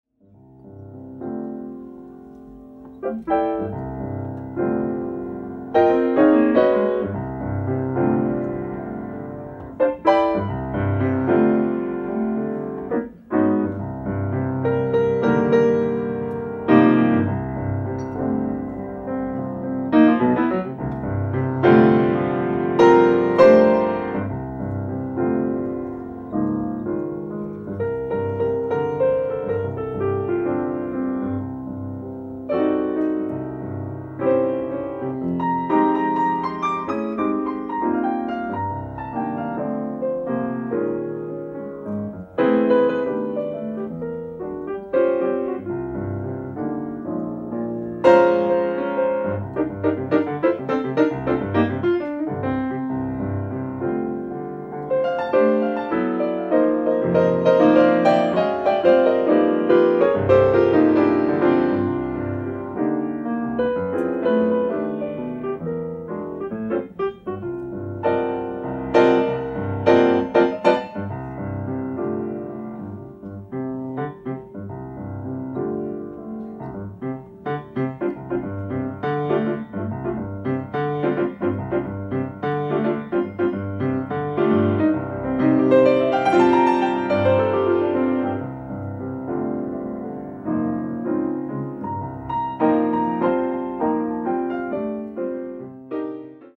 ライブ・アット・アンティーブ・ジャズ、ジュアン・レ・パン、フランス 07/21/1985
今年再放送されたピカピカ音質で収録！！